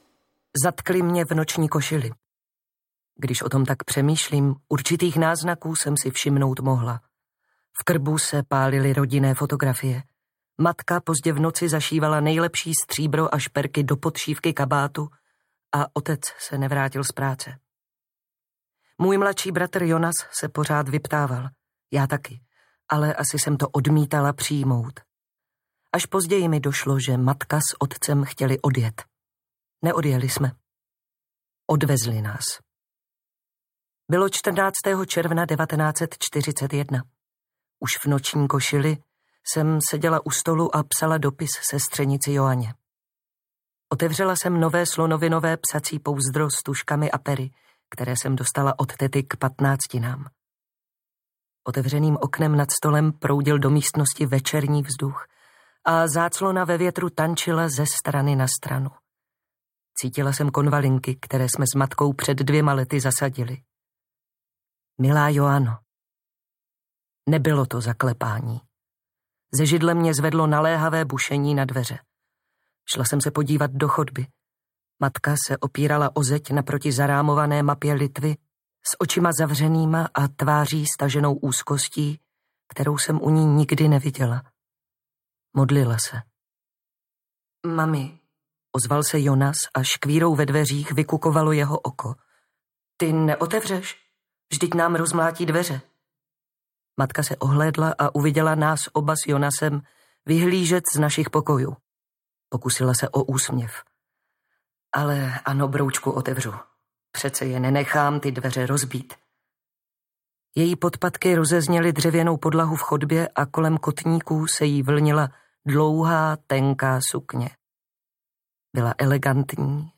V šedých tónech audiokniha
Ukázka z knihy